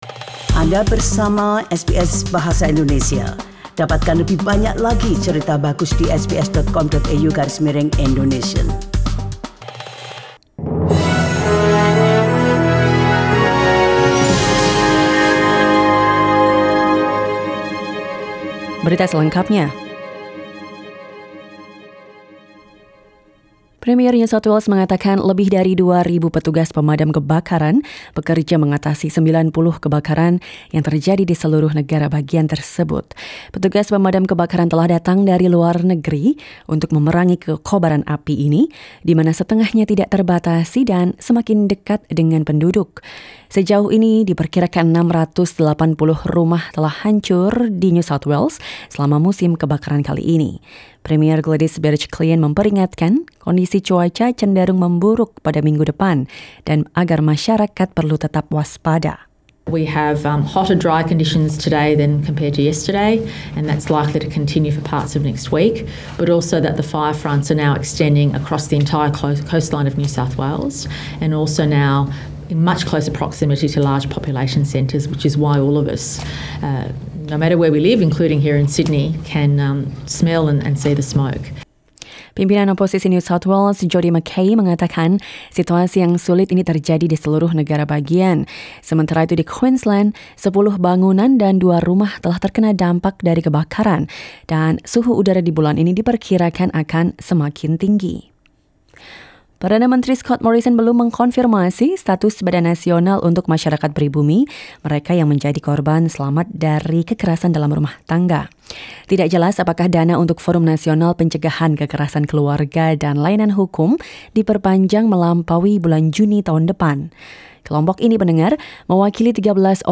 SBS Radio news in Indonesian - 6 Desember 2019